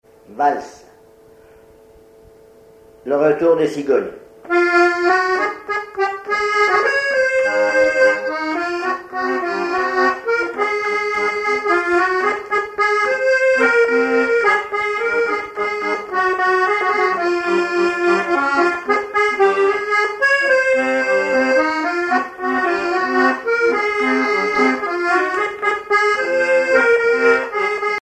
accordéon(s), accordéoniste
danse : valse musette
Répertoire à l'accordéon chromatique
Pièce musicale inédite